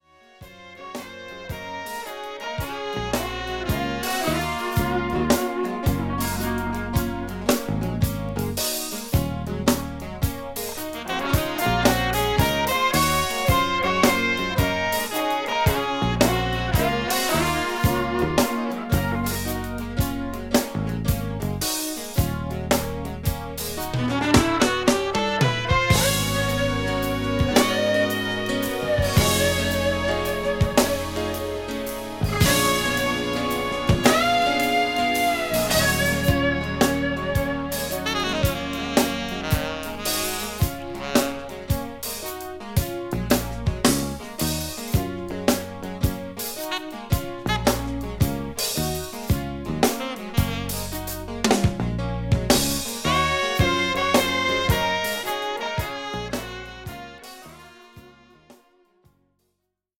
FUSION
リーダー作でありながらデュオ・アルバムのような趣も感じさせる好フュージョン作です。